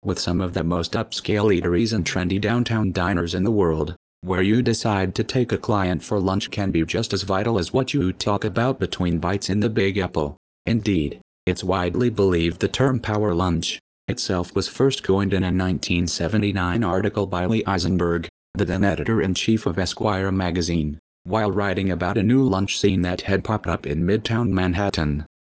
Voice Demo
Cepstral David 16k (U.S. English)